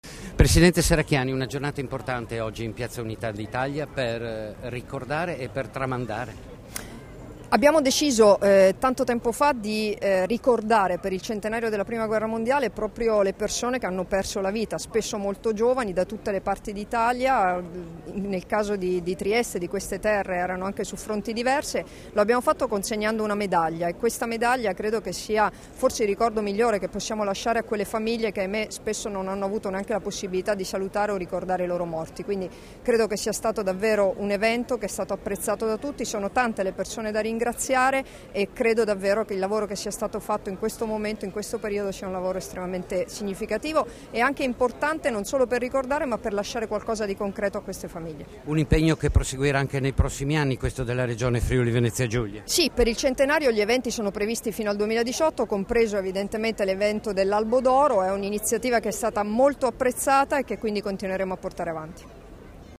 Dichiarazioni di Debora Serracchiani (Formato MP3) [1005KB]
alla cerimonia di lettura dei nomi dei Caduti della Grande Guerra, rilasciate in piazza Unità d'Italia a Trieste il 16 aprile 2016